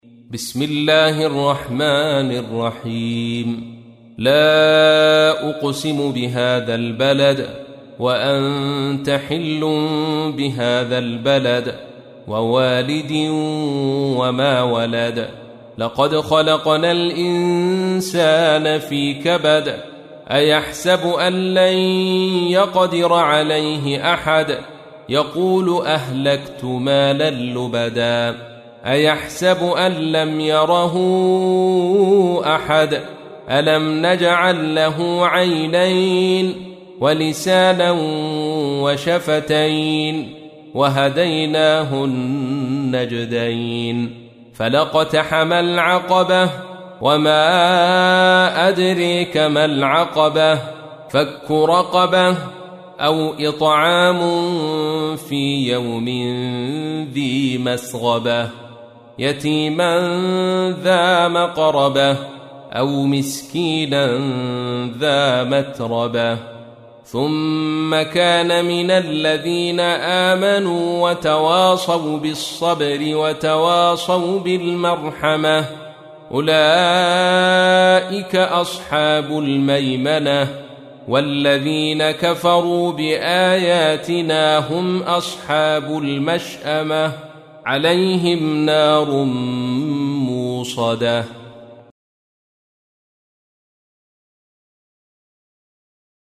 تحميل : 90. سورة البلد / القارئ عبد الرشيد صوفي / القرآن الكريم / موقع يا حسين